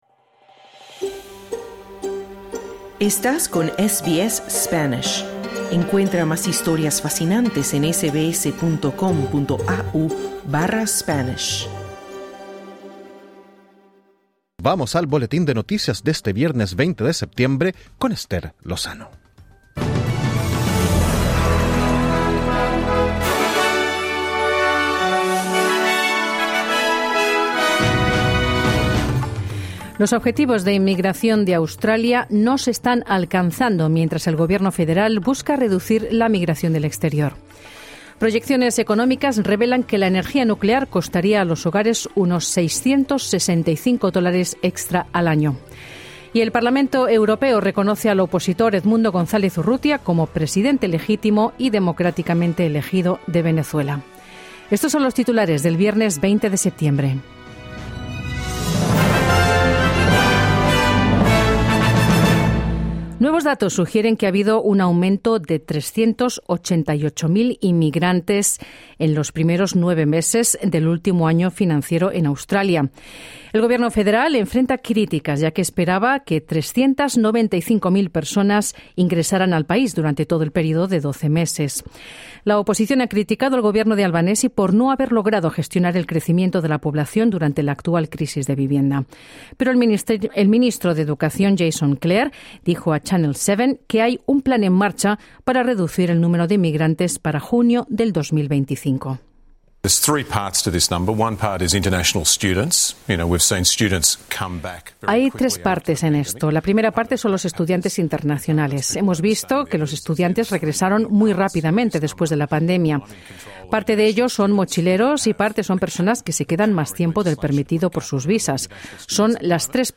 Noticias SBS Spanish | 20 septiembre 2024